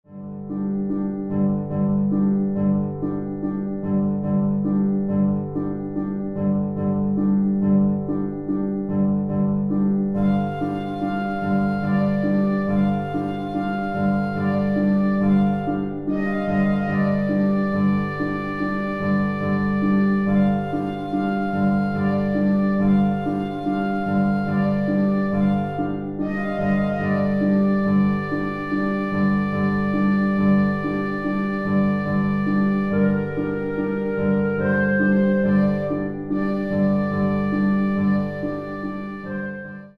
midi produced sound file here